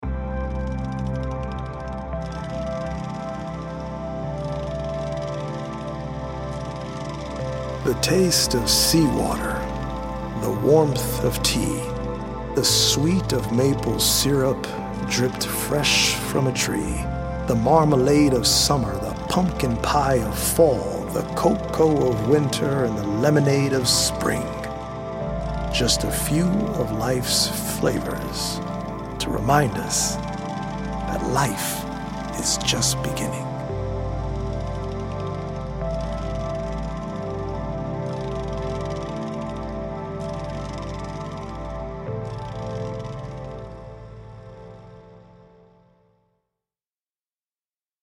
healing audio-visual poetic journey
healing Solfeggio frequency music
EDM producer